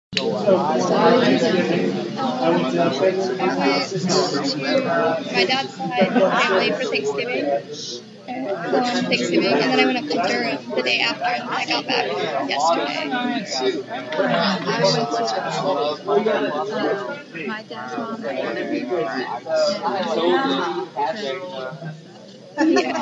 有摩托艇和其他干扰。
标签： 印度 面试 说话